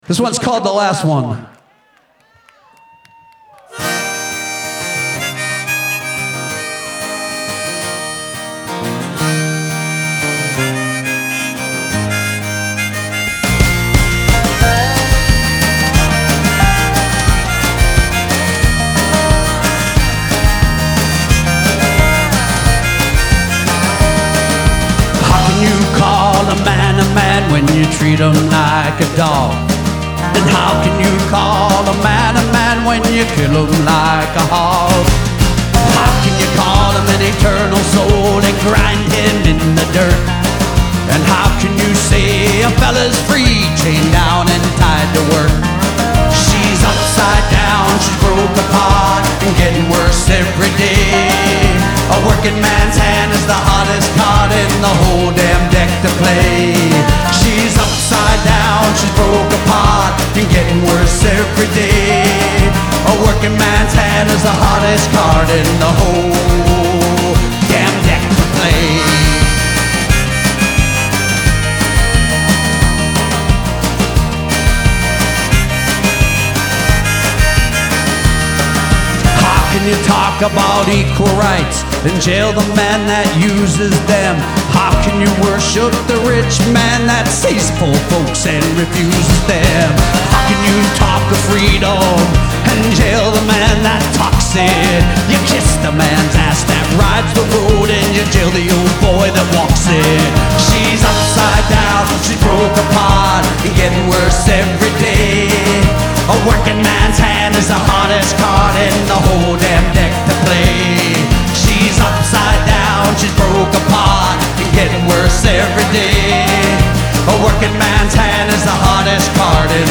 Live at Ryman Auditorium